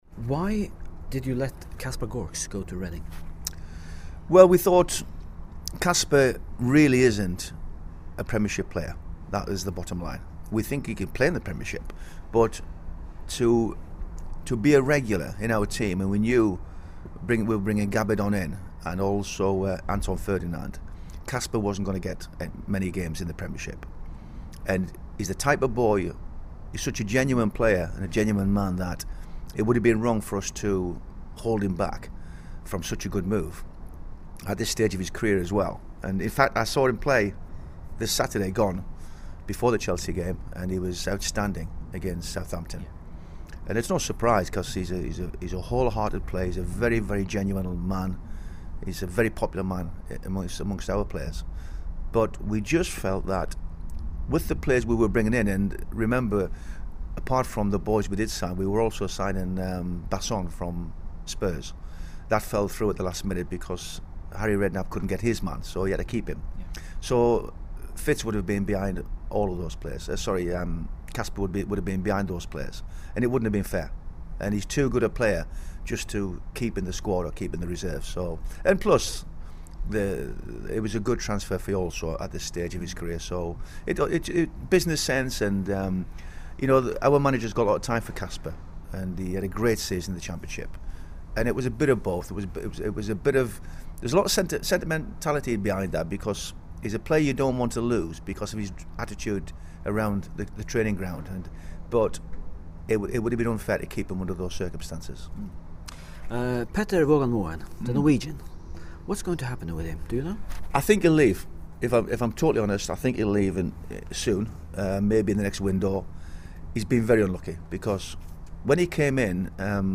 All 4 parts of the interview here: